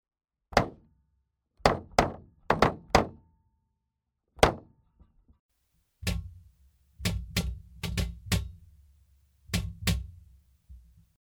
Here is a Dean Markley Artist XM transducer sample. First half is attached to drum shell. Second half is hanging over boom stand in center of kick. Definitely usable as a trigger.